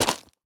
move_5.ogg